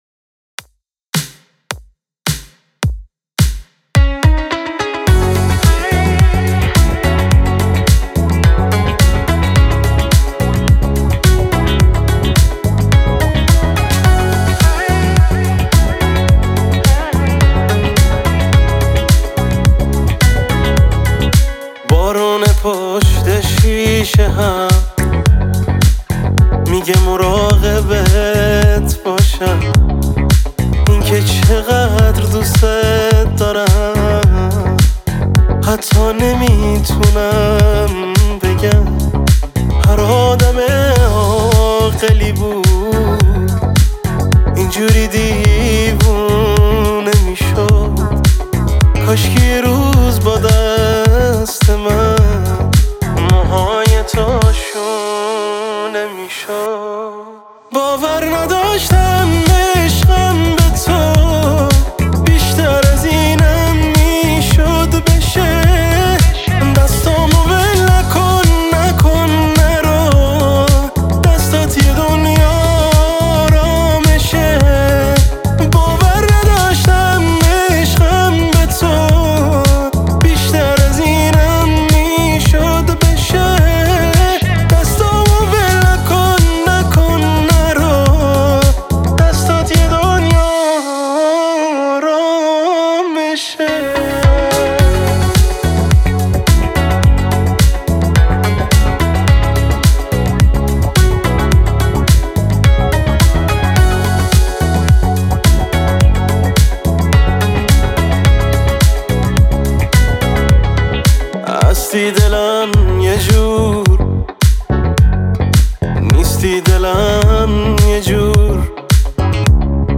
آهنگ احساسی آهنگ ایرانی